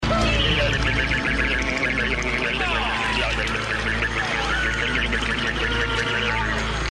this noise when someone turns the tables on you.